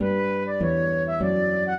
flute-harp
minuet2-10.wav